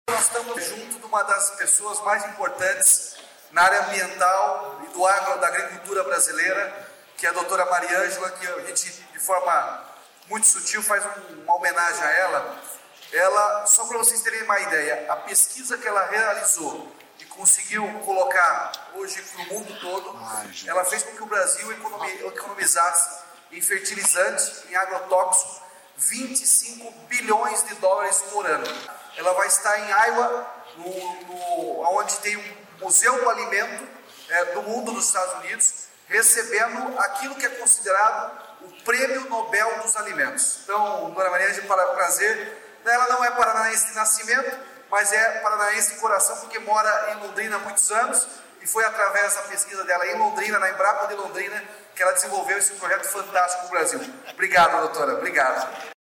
Sonora do governador Ratinho Junior